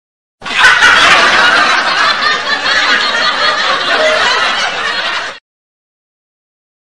Suara Orang Tertawa Terbahak-Bahak
Kategori: Suara ketawa
Keterangan: Suara tawa terbahak-bahak yang penuh keceriaan, diiringi dengan suasana yang menghibur.
suara-orang-tertawa-terbahak-bahak-id-www_tiengdong_com.mp3